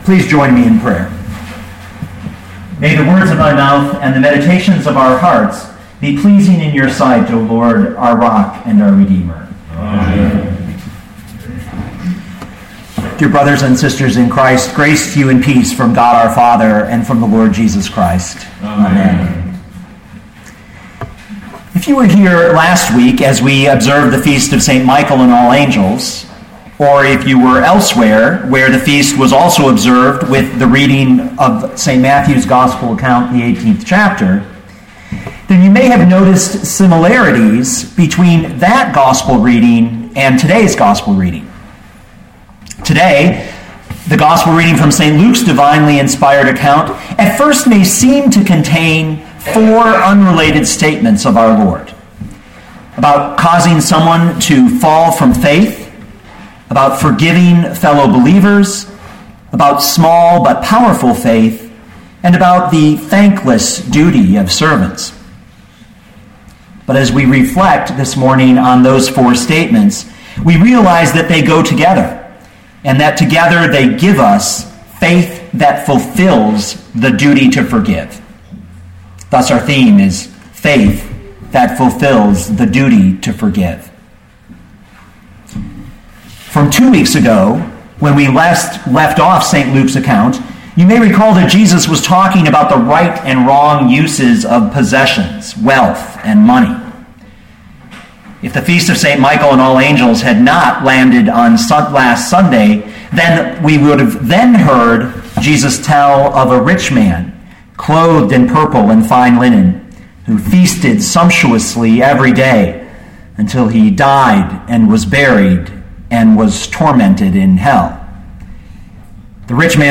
2013 Luke 17:1-10 Listen to the sermon with the player below, or, download the audio.